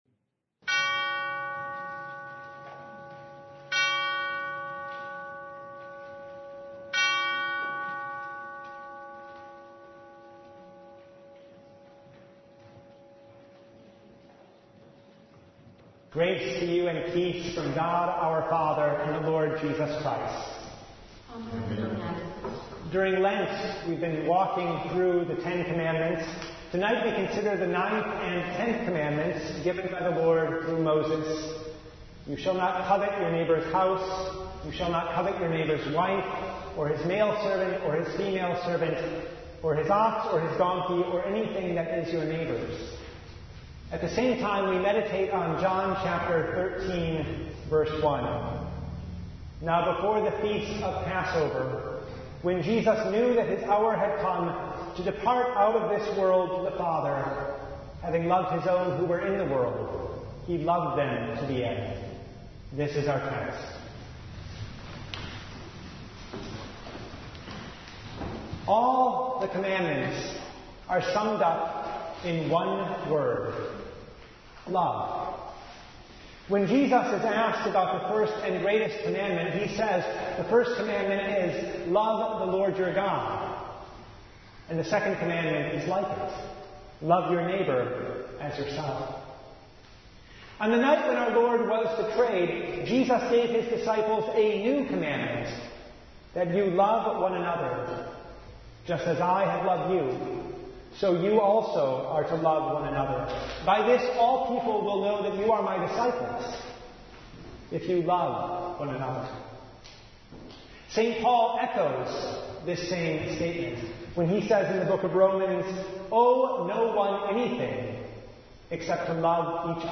Service Type: Maundy Thursday